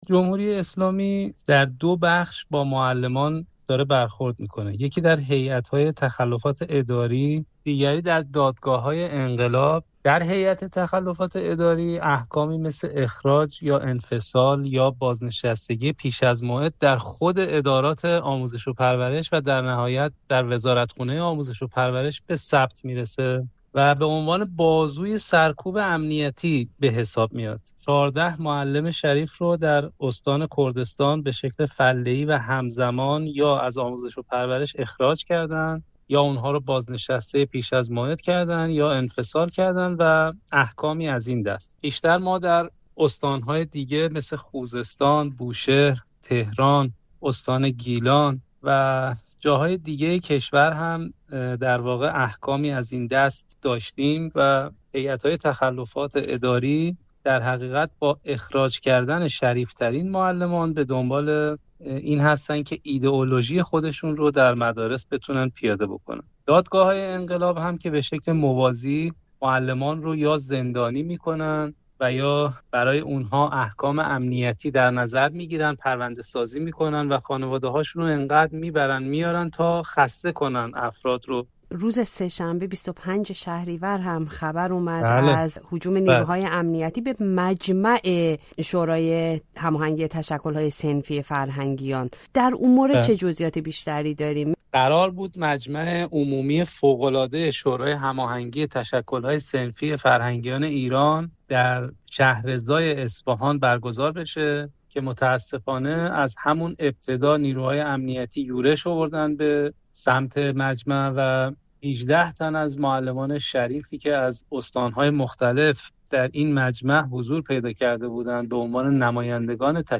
تداوم سرکوب فعالان صنفی معلمان ایران در گفت‌وگو با